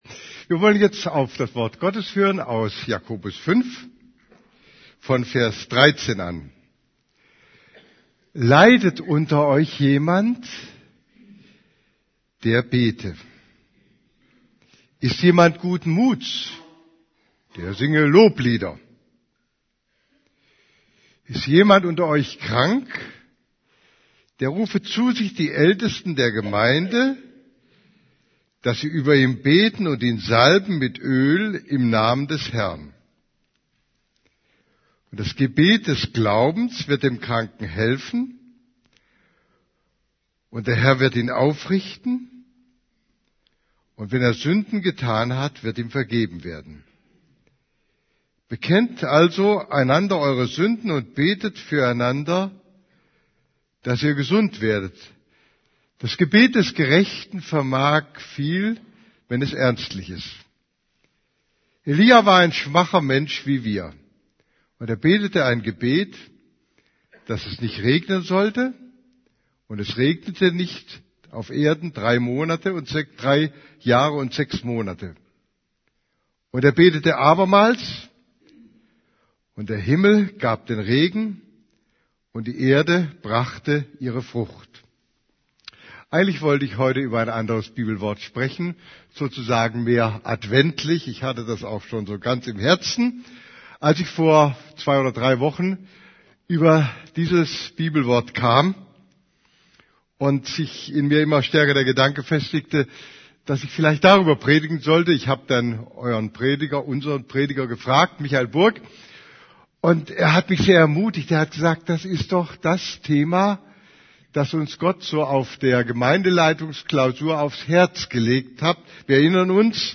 > Übersicht Predigten Gebet für Kranke Predigt vom 04.